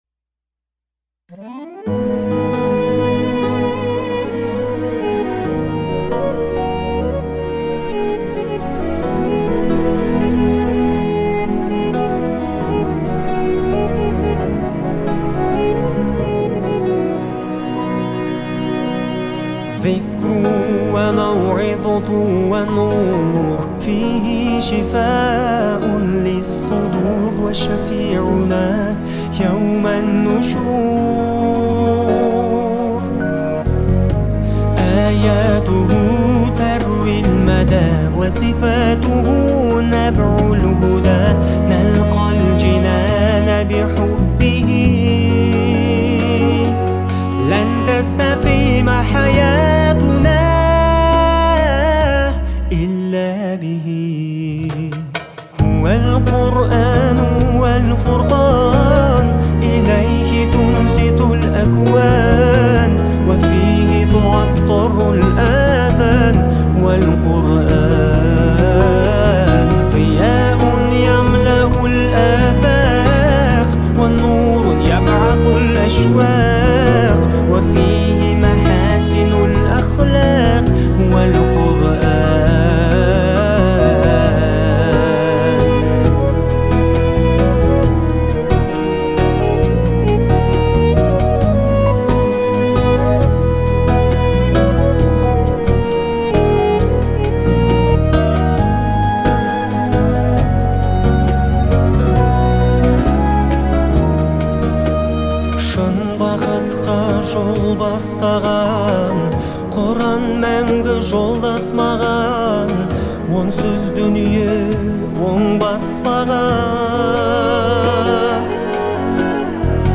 его голос звучит проникновенно и искренне